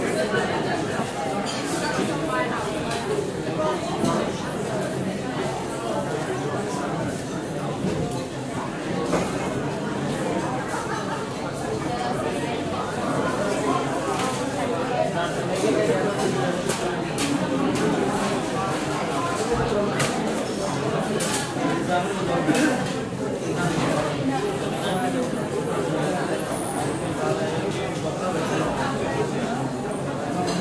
dining.ogg